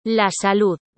Deuxièmement, sachez que les mots qui se terminent par une consonne autre que « n » et « s », voit leur accent tonique placé sur la dernière syllabe.
Exemples : « la mujer » (la femme) ou encore « la salud » (la santé)